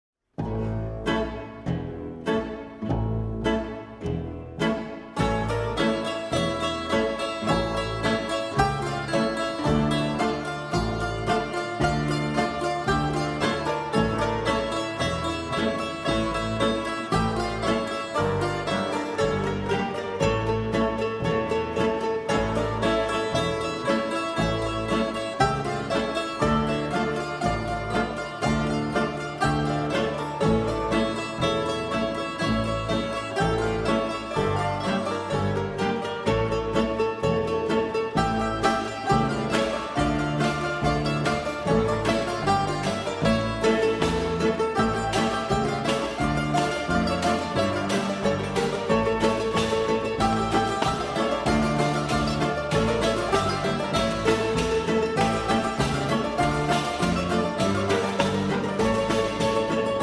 Chamber Ensemble
by Traditional Croatia, Bulgaria